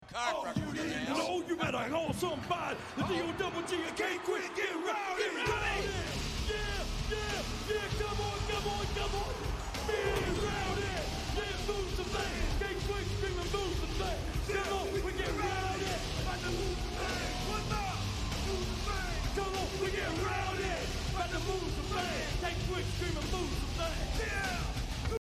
shouted enthusiastically, often